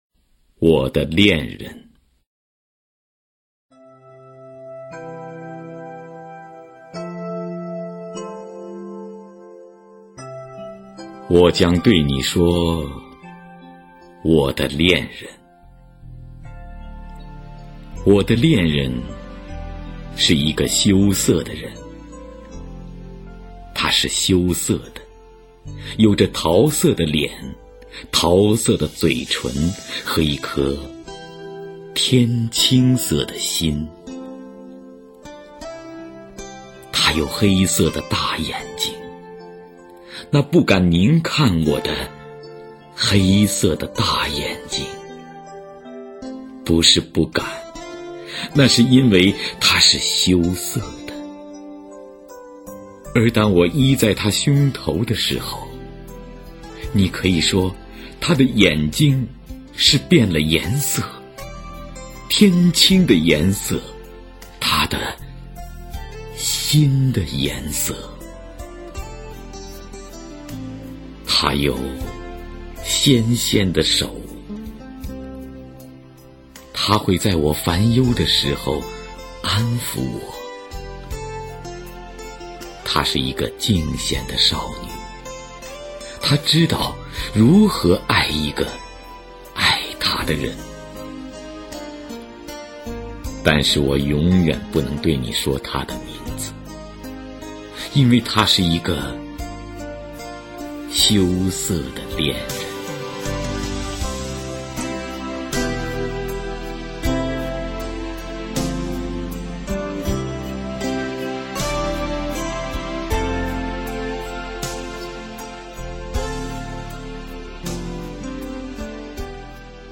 首页 视听 经典朗诵欣赏 网络精选——那些张扬个性的声音魅力